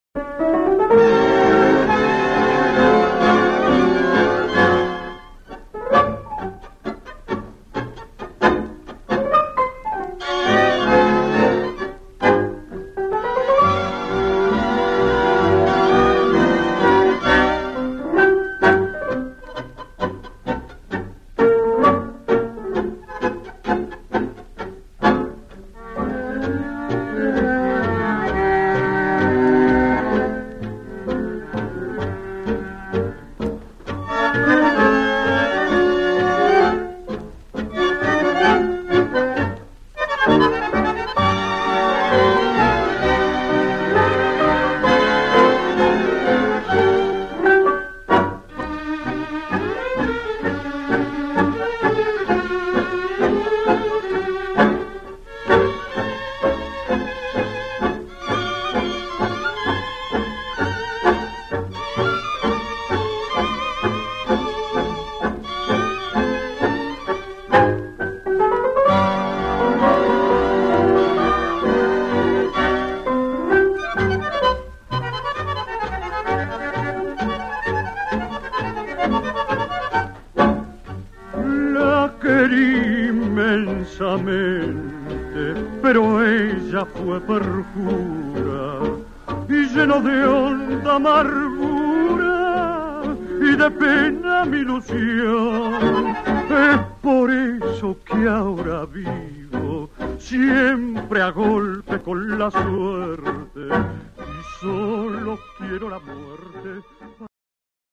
Pozdravljeni ljubitelji dobre tango glasbe!